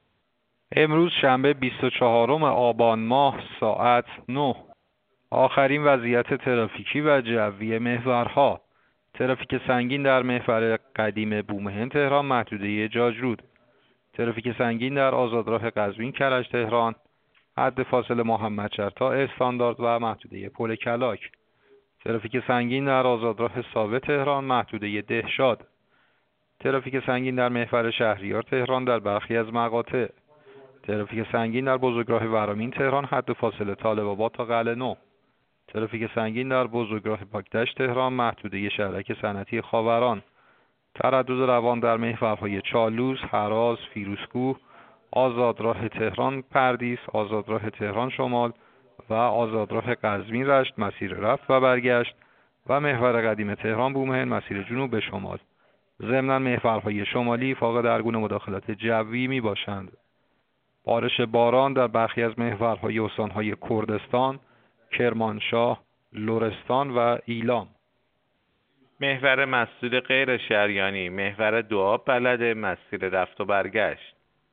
گزارش رادیو اینترنتی از آخرین وضعیت ترافیکی جاده‌ها ساعت ۹ بیست و چهارم آبان؛